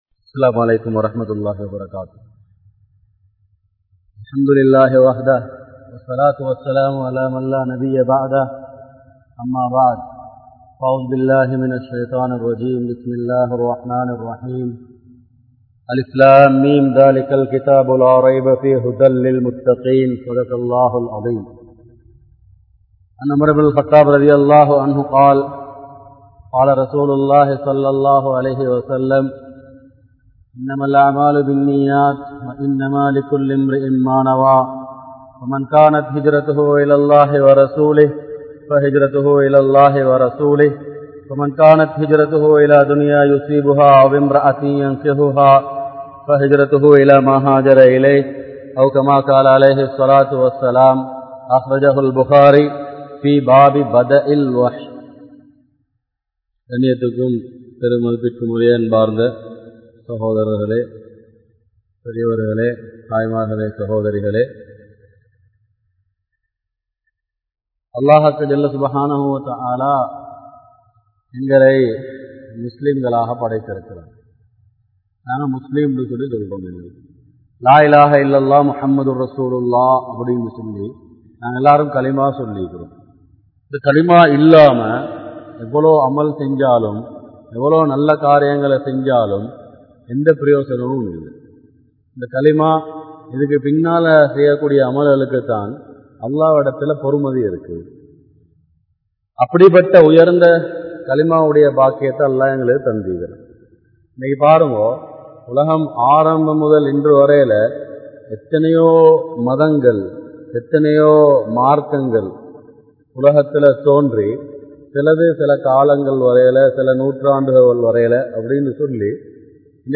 Islamiya Kudumpa Vaalkai(Part 01) | Audio Bayans | All Ceylon Muslim Youth Community | Addalaichenai
Colombo 12, Grand Jumua Masjith